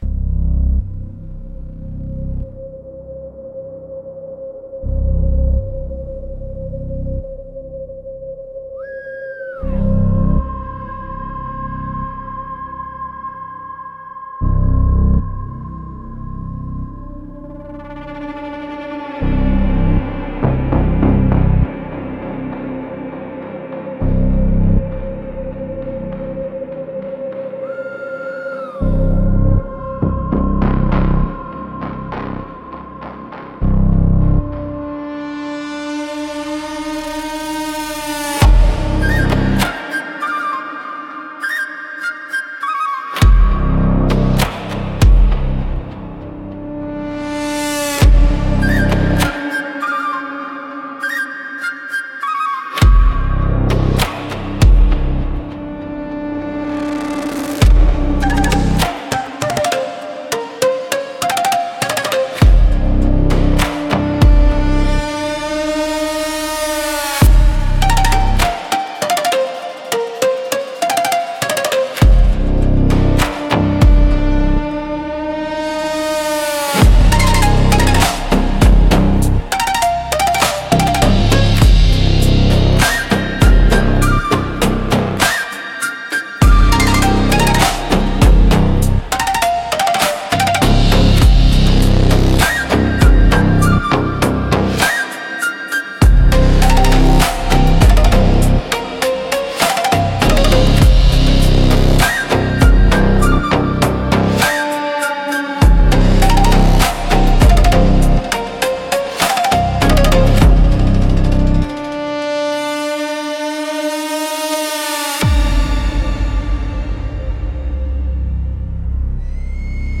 Instrumentals - Memory of a Pulse